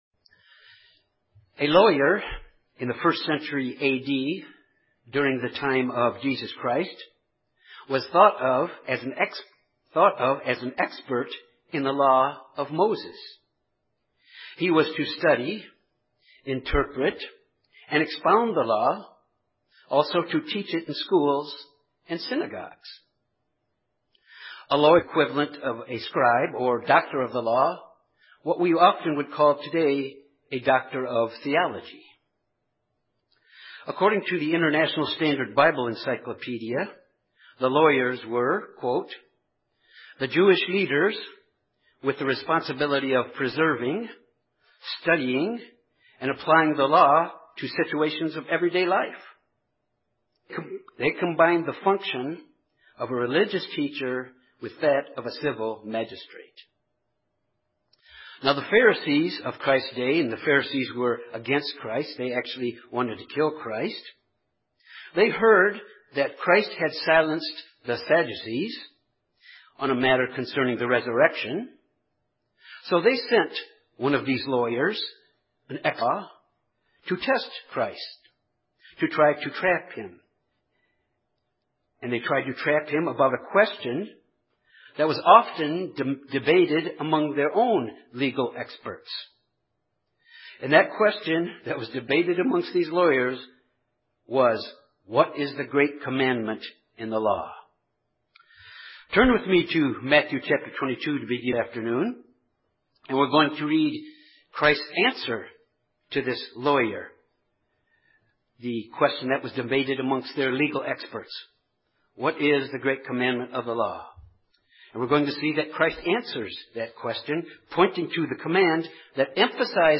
When Jesus was asked what is the greatest commandment in the law, He replied 'You shall love the lord your God with all your heart, with all your soul, and with all your mind.' This sermon examines this “greatest commandment” and its application to the disciples of Jesus and mankind in general.